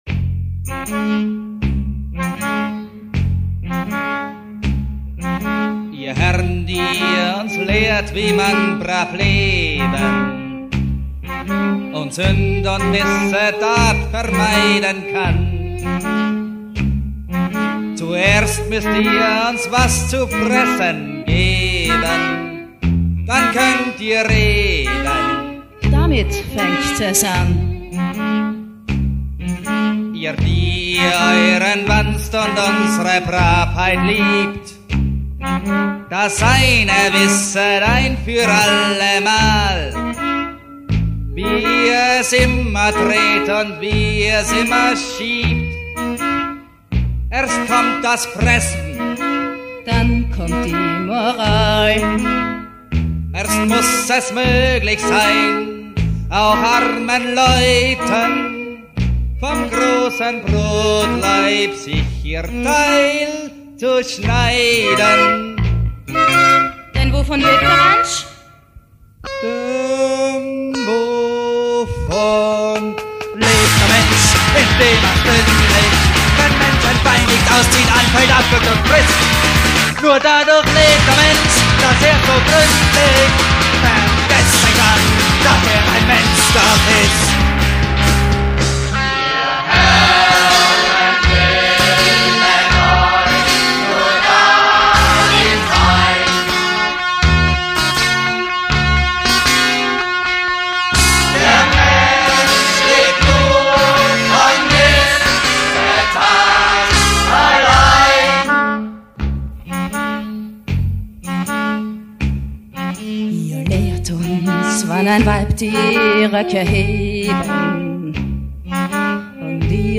Aufführungen im EKH Herbst 1994 - September 1995
Das entstandene Ensemble kommt also ganz und gar aus Haus und Umfeld, keine Profis nirgendwo, kein "Künstler", keine krude Wissenschaft ist da um uns die Intuition zu rauben.
Die Musik zu dem Ding hat der wüste Weill geschrieben, ganz unglaubliches Material,wir setzen dem noch eins drauf, indem wir dem Dreigroschenorchester die Mackie-Messer-Band gegenüberstellen, und so in der Lage sind, euch Geigen und E-Gitarre gleichzeitig und wechselweise um die Ohren zu knallen.